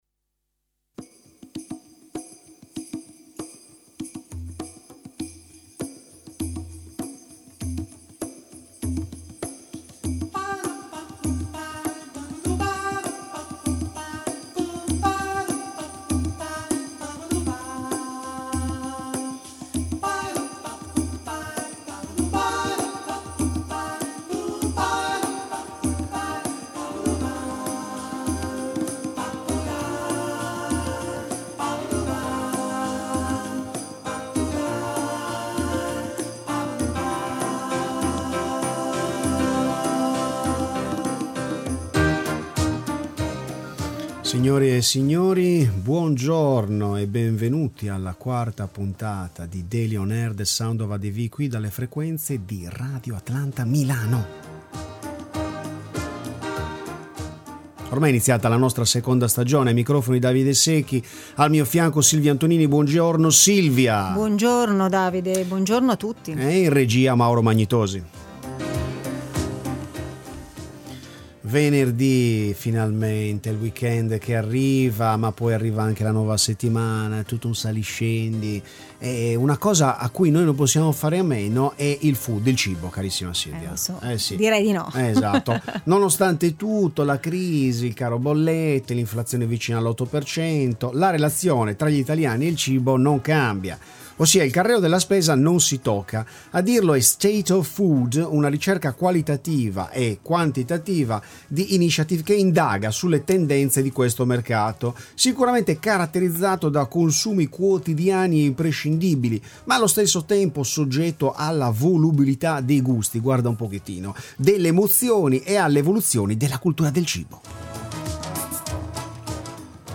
di argomenti invece un’autentica schiera Gli italiani e il cibo nella ricerca “State Of Food” di Initiative Gli Azzurri del volley campioni anche sui social: i dati di Nielsen Il mondo della’udio, dei podcast nell’intervista